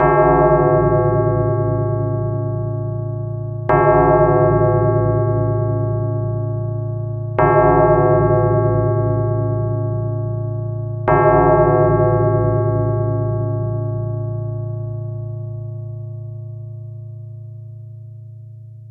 bells
bell bells bell-set bell-tone bong cathedral chime church sound effect free sound royalty free Animals